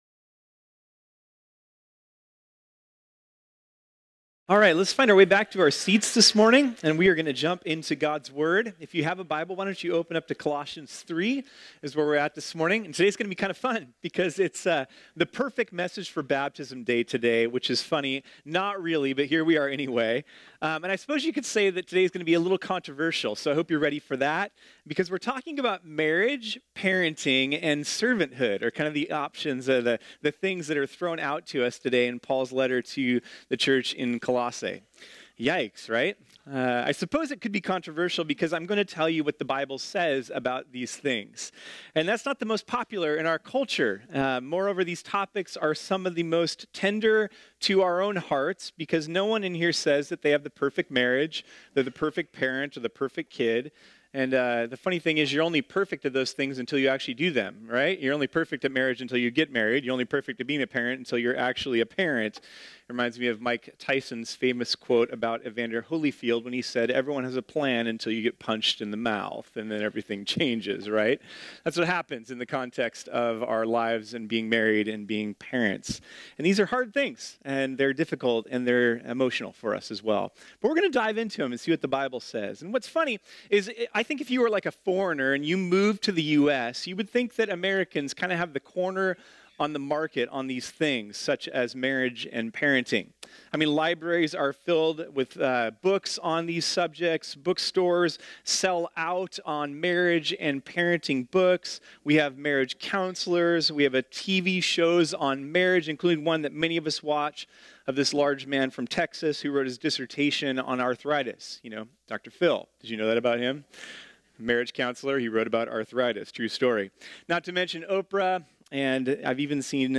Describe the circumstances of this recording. This sermon was originally preached on Sunday, November 18, 2018.